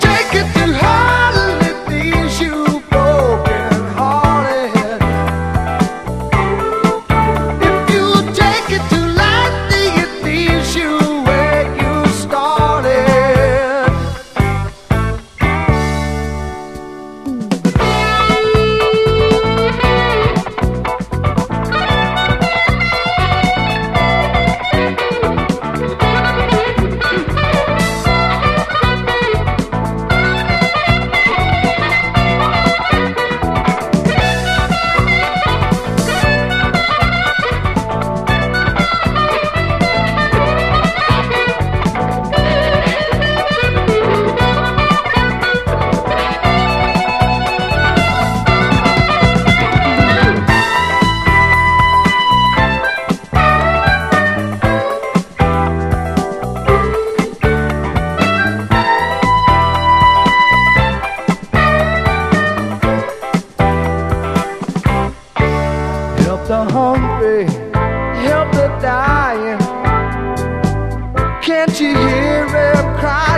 ROCK / S.S.W./A.O.R. / MODERN POP / 70'S (US)
高揚感を煽る分厚いハーモニーとテクニカルなサウンドをベースに、艶を帯びた泣きメロが熱く弾ける
カラフルで魔法がかりのポップ・ナンバーを連発！